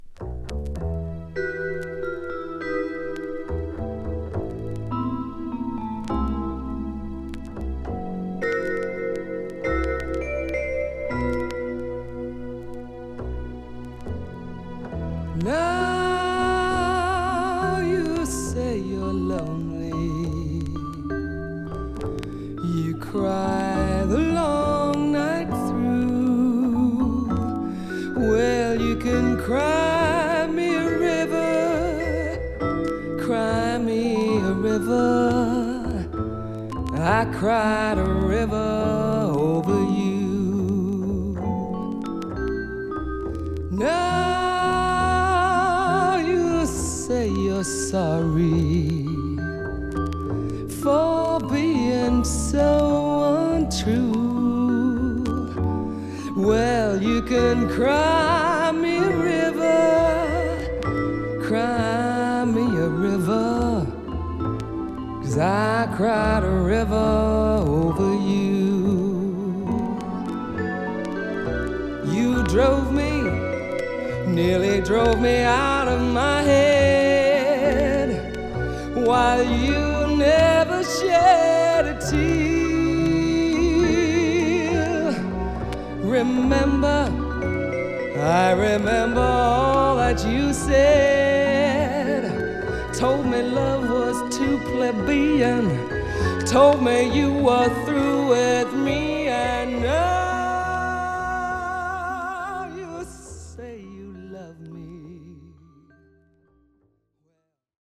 英国のポップおよびジャズシンガー
ジャズスタンダード